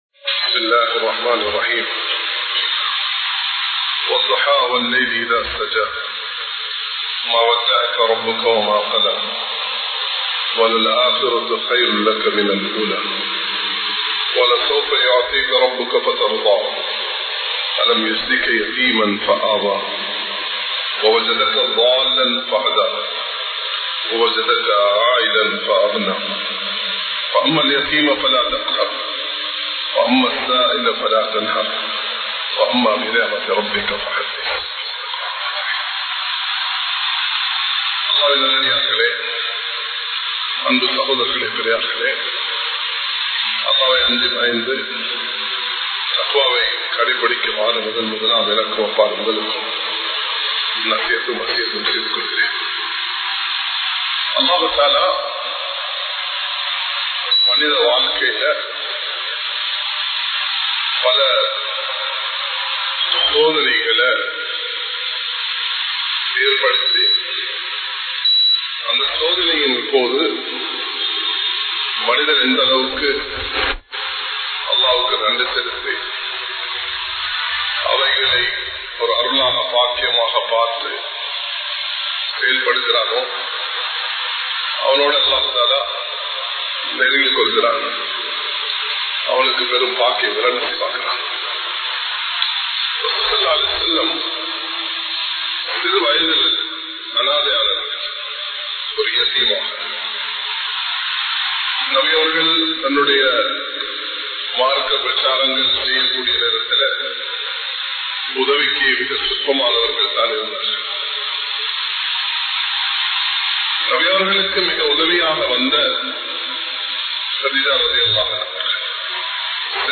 சூரா ழுஹா கற்றுத் தரும் படிப்பினைகள் | Audio Bayans | All Ceylon Muslim Youth Community | Addalaichenai
Kollupitty Jumua Masjith